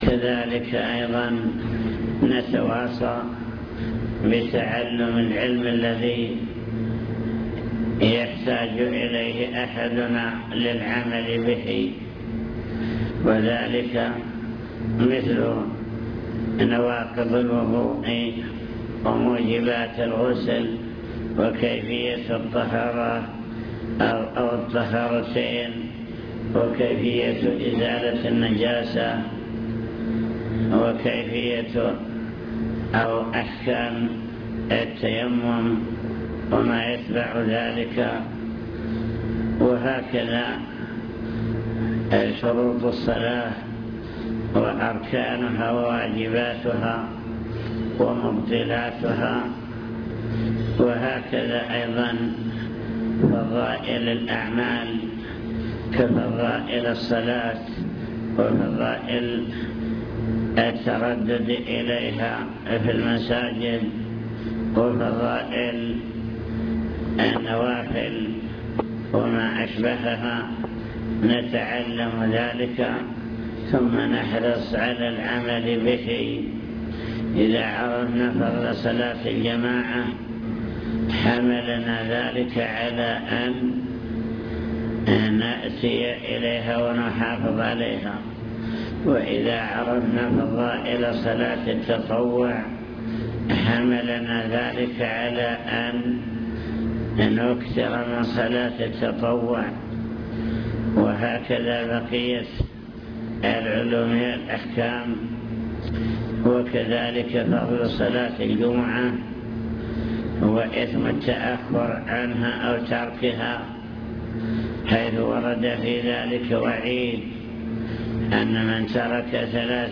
المكتبة الصوتية  تسجيلات - لقاءات  كلمة للمعلمين وطلاب التحفيظ وصايا من الشيخ